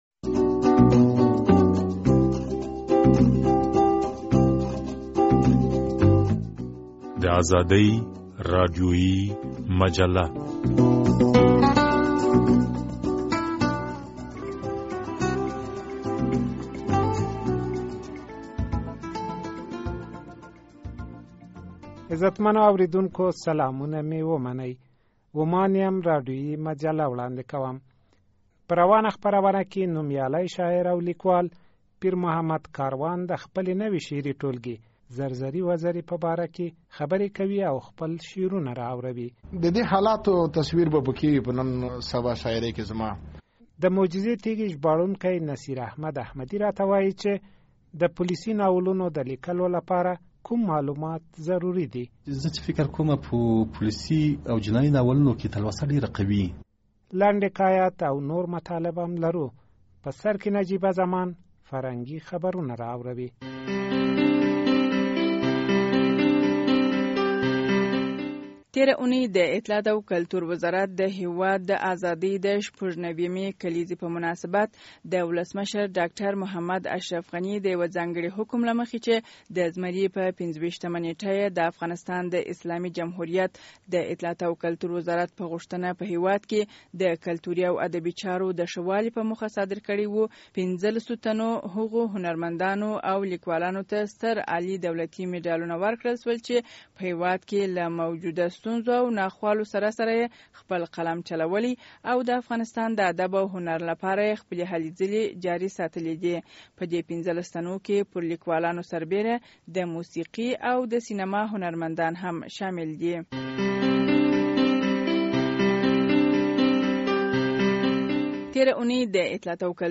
په روانه خپرونه کې نوميالي شاعر او ليکوال پيرمحمد کاروان، د خپلې نوې شعري ټولګې (زرزري وزرې) په باره کې خبرې کوي او شعرونه را اوروي.